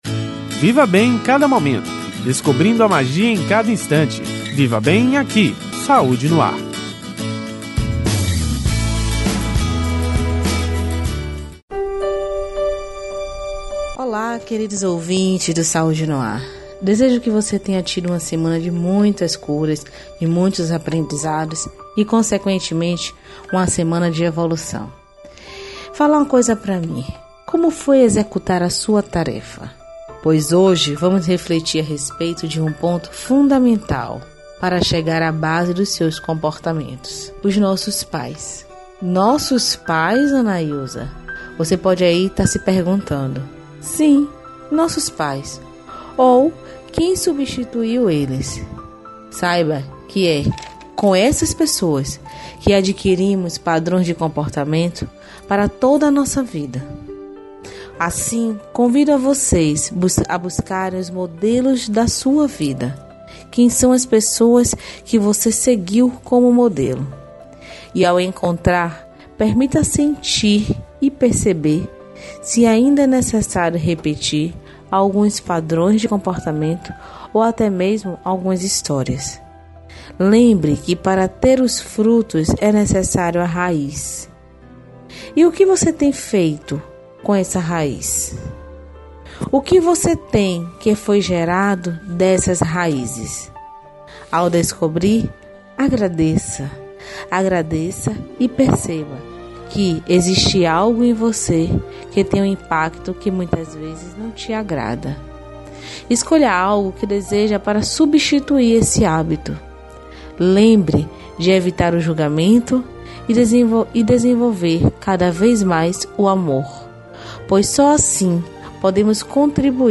Devemos nos dar conta de que muito do que somos aprendemos com eles. O quadro é exibido toda sexta-feira no Programa Saúde No Ar veiculado pela Rede Excelsior de Comunicação: AM 840, FM 106.01, Recôncavo AM 1460 e Rádio Saúde no ar / Web.